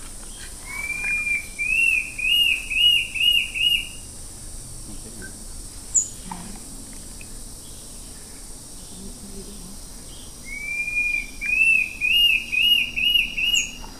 Tufted Antshrike (Mackenziaena severa)
Sex: Male
Life Stage: Adult
Detailed location: Horto Florestal Tupi
Condition: Wild
Certainty: Photographed, Recorded vocal